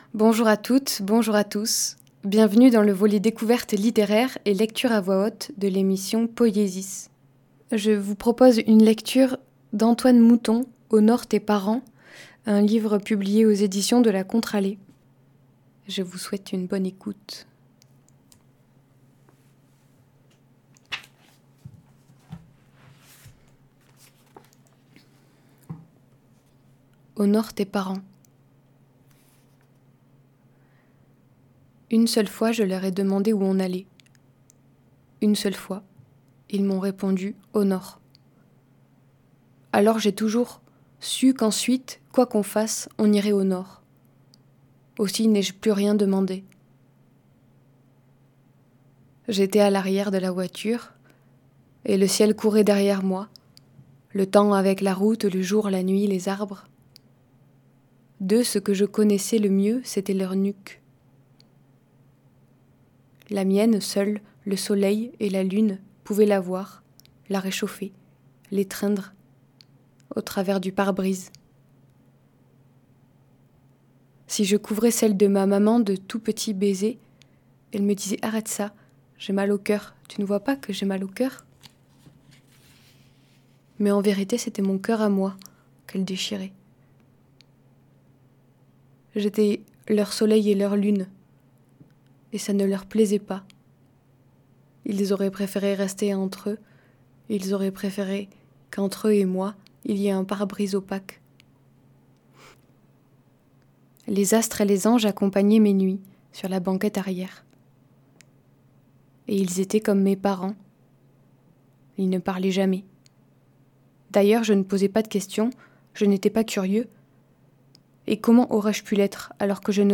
Dire, écouter, tendre – un silence, à voix haute, à deux voix ; tantôt textes originaux, tantôt corpus d’auteur.ices qui touchent notre sensibilité. Poïesis est une espace radiophonique dédié aux voix poétiques.